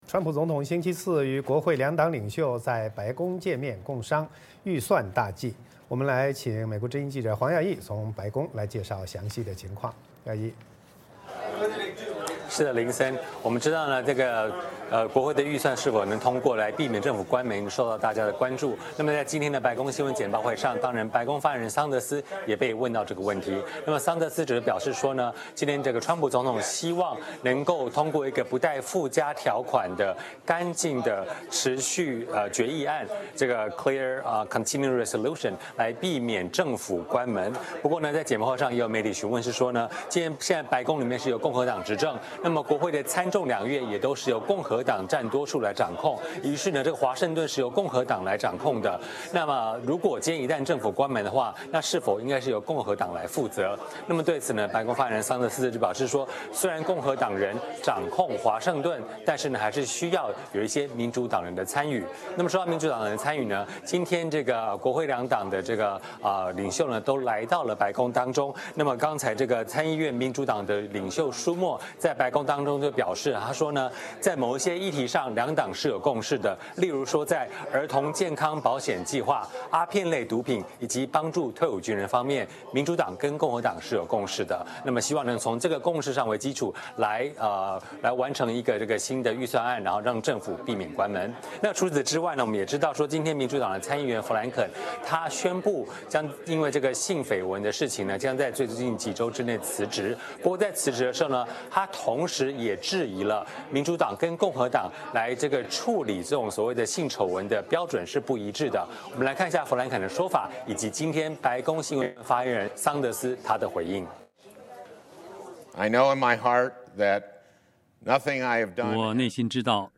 VOA连线：白宫回应政坛性丑闻，川普纪念珍珠港事件